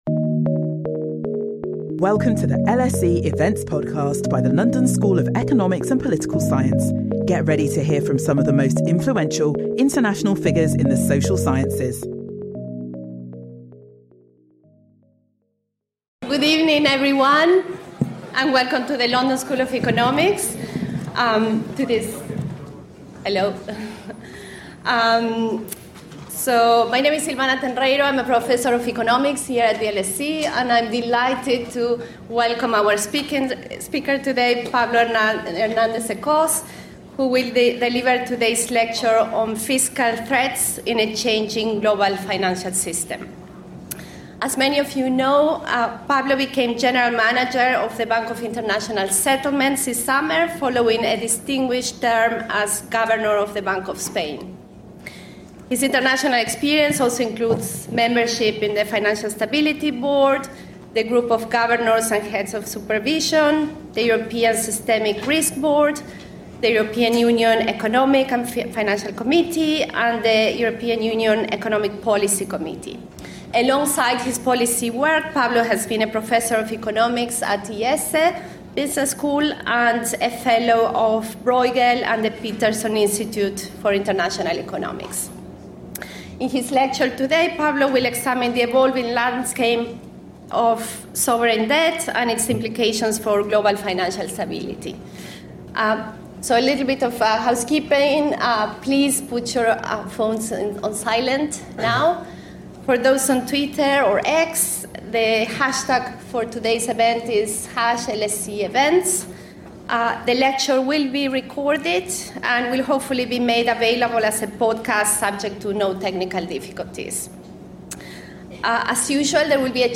This lecture will discuss how policymakers should address these challenges by employing a carefully selected mix of tools that spans fiscal, monetary and prudential policy.